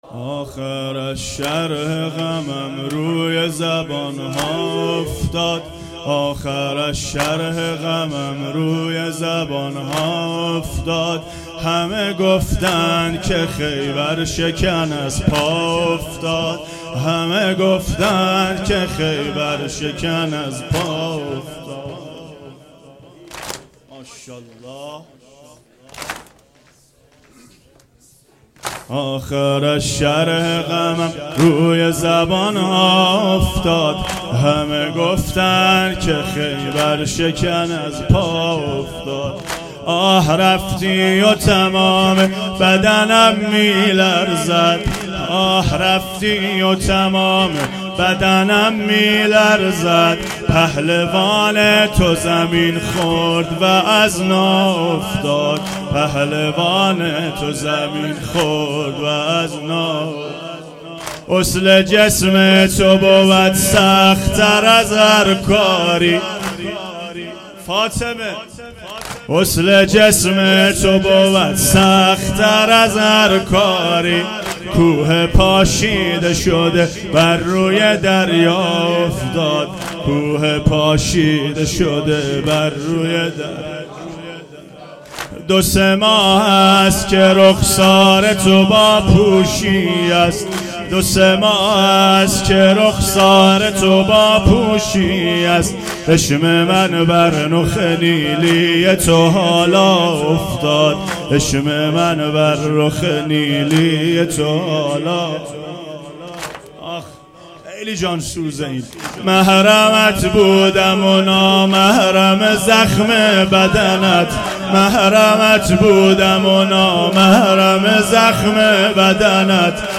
واحد تند شب پنجم فاطمیه